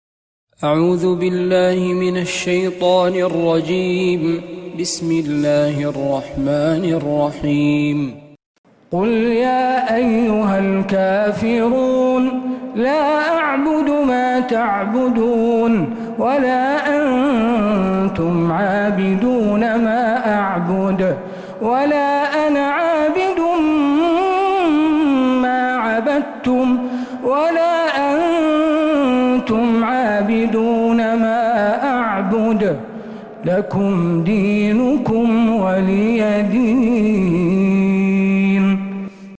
سورة الكافرون من تراويح الحرم النبوي